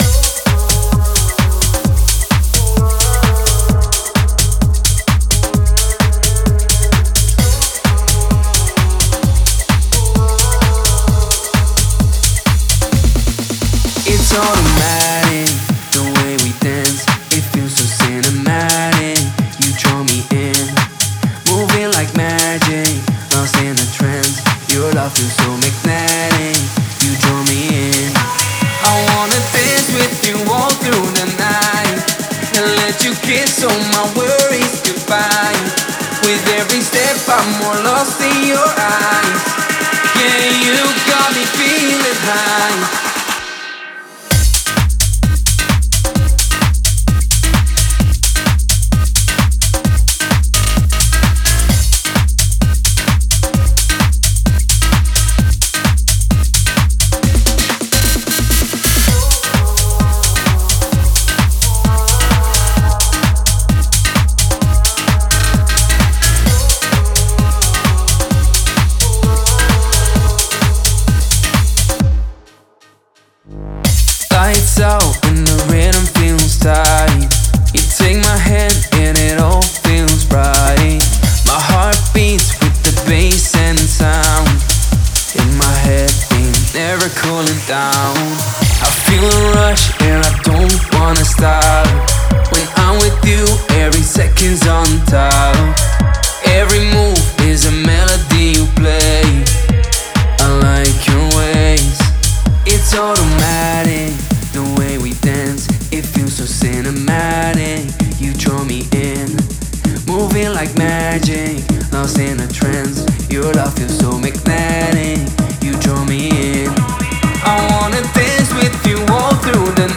130 Bpm – C#minor